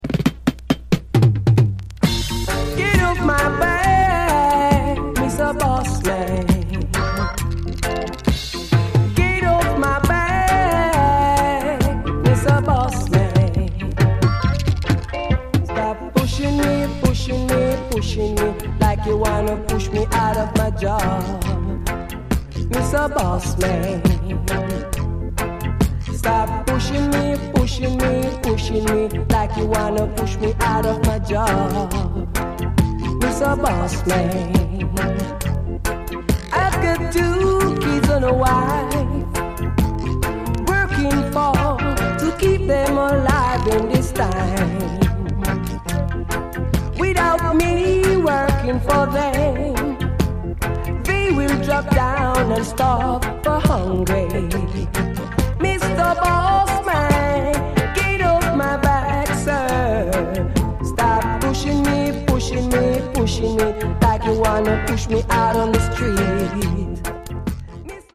Late ’70s-early ’80s reggae from Jamaica, date not known.
Incls. dope dub